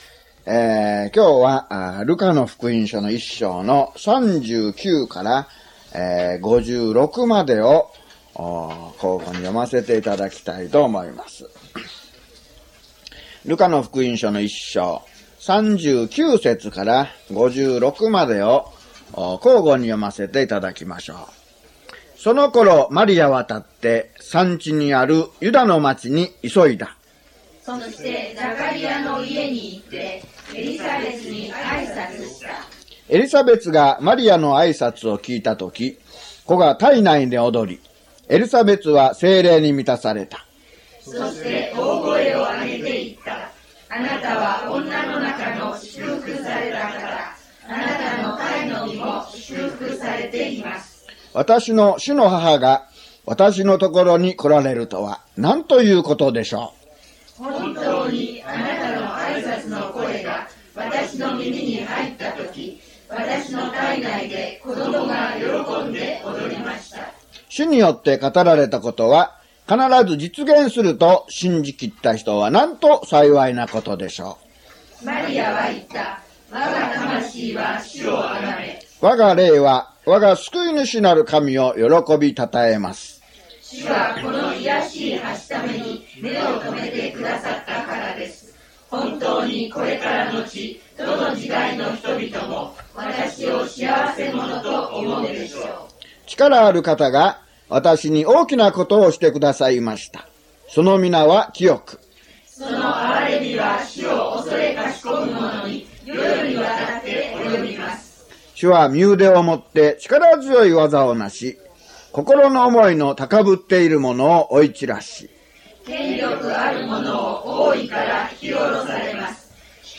luke005mono.mp3